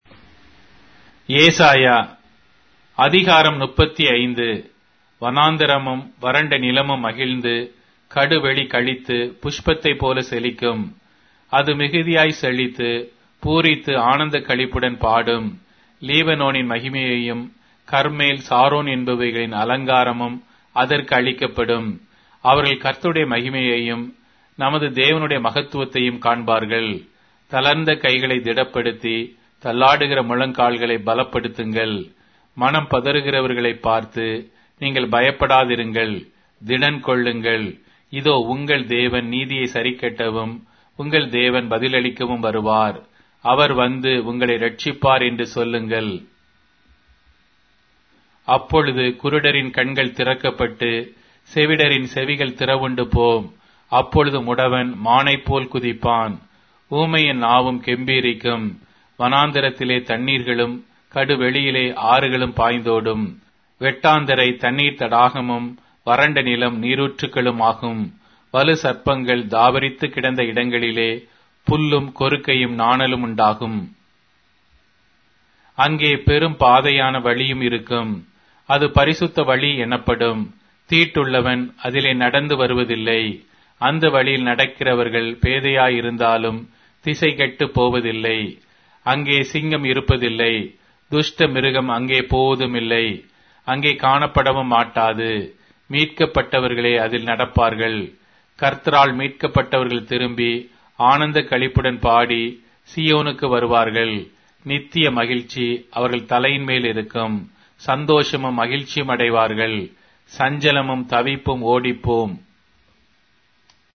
Tamil Audio Bible - Isaiah 50 in Alep bible version